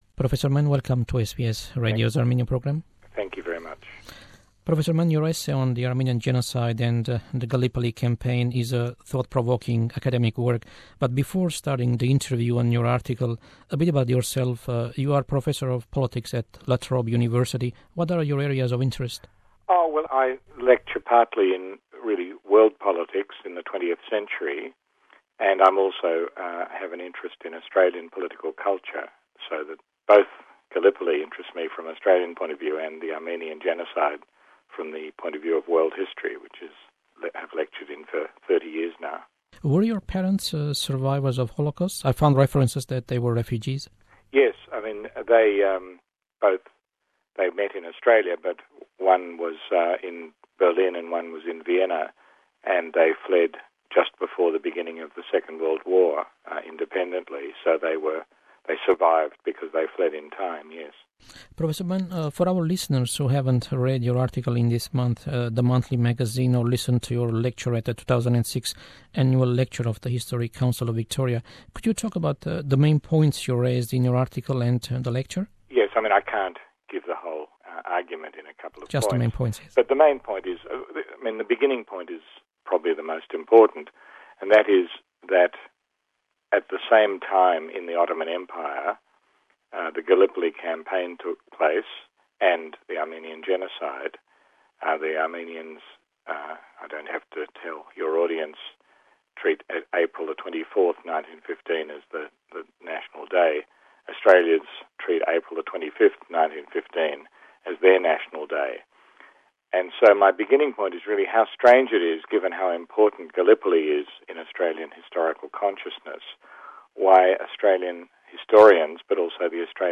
Why Australian historians & also the public have never reflected to the connection between Gallipoli and the Armenian Genocide? An interview with Professor Robert Manne about his views on this topic puzzling the Armenian community and historians like him.